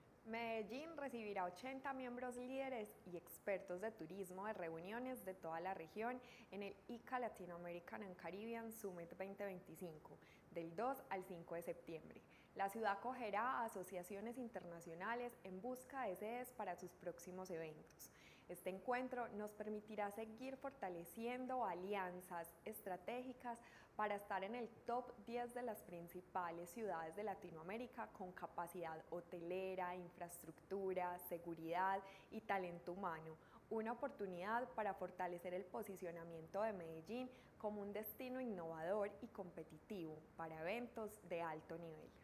Declaraciones secretaria (e) de Turismo y Entretenimiento, Ana María Mejía
Declaraciones-secretaria-e-de-Turismo-y-Entretenimiento-Ana-Maria-Mejia.mp3